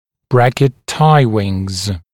[‘brækɪt ‘taɪwɪŋz][‘брэкит ‘тайуинз]подвязочные крылья брекета